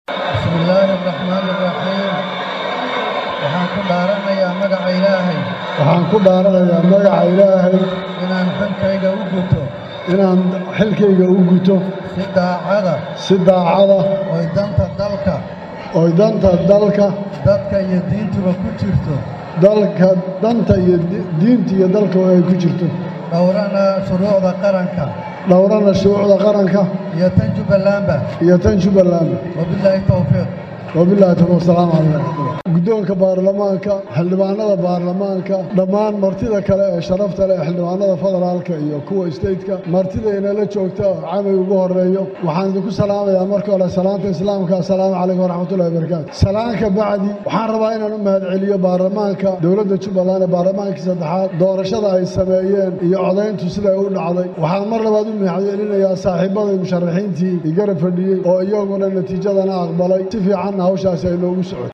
Intaas ka dib, madaxweynaha dib loo doortay ee Jubbaland Axmad Madoobe ayaa Khudbad uu jeediyay markii loo xaqiijiyay inuu ku guuleystay doorashadii.